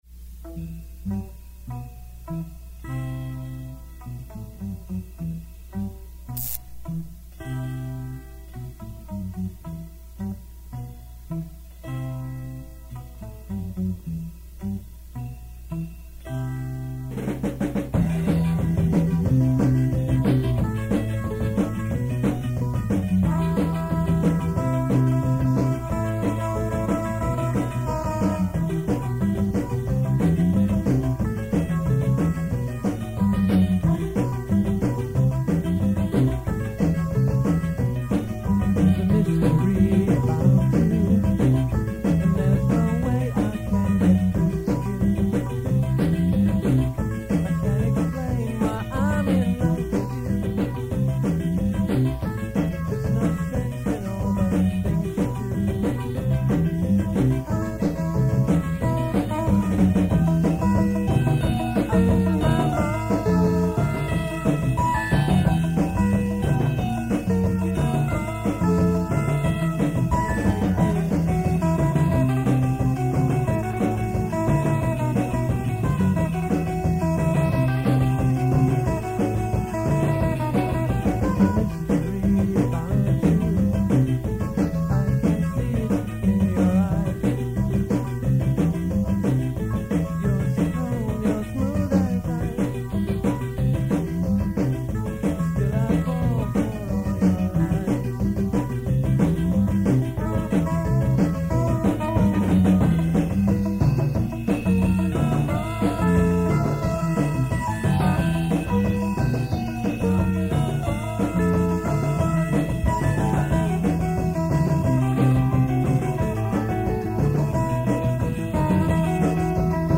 Keep in mind that these are from very old cassette tapes, so sound quailty is definitely an issue.
The band played some covers from The English Beat, Madness and The Specials, but mostly played original material in the style of ska and reggae.
written by Silent Q: from the Rehearsal tape